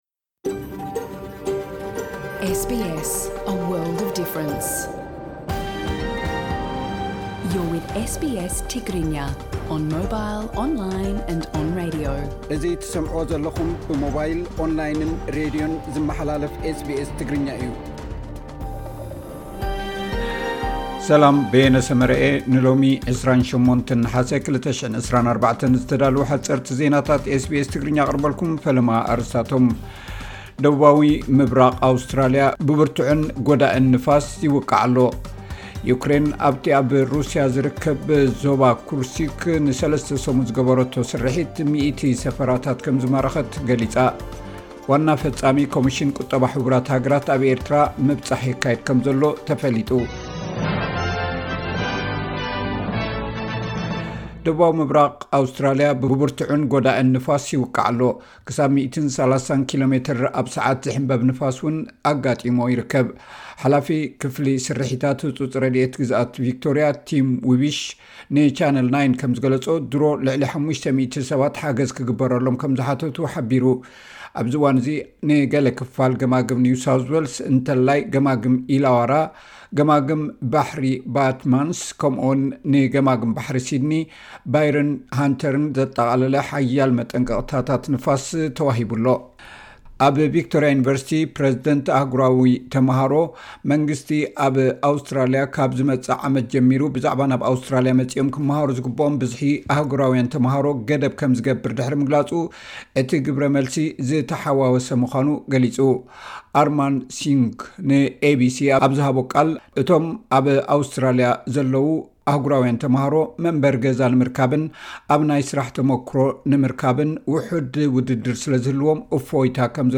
ሓጸርቲ ዜናታት ኤስ ቢ ኤስ ትግርኛ (28 ነሓሰ 2024)